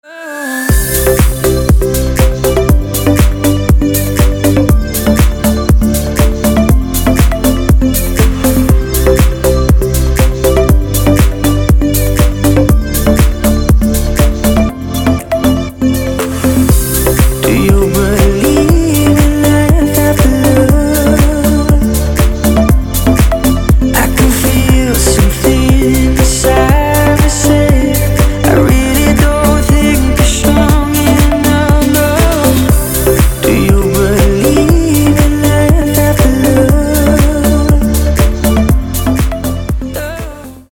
deep house
веселые
Cover
tropical house
vocal
битные